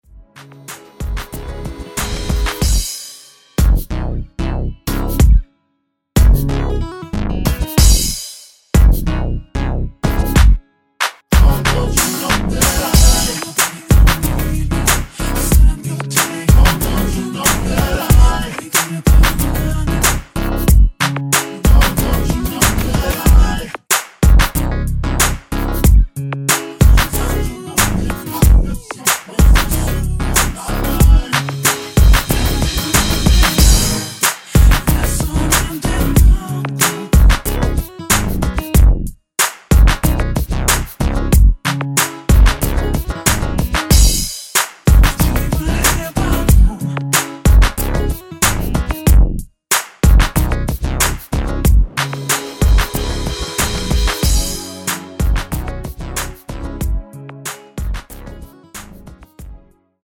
C#m
앞부분30초, 뒷부분30초씩 편집해서 올려 드리고 있습니다.